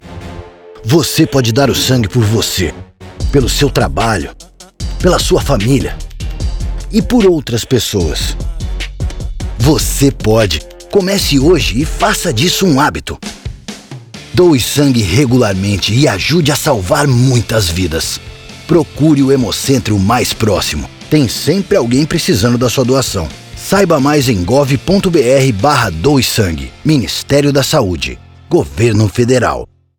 Spot feito pelo Ministério da Saúde em 2024
Spot-Doacao-de-Sangue-30Seg.mp3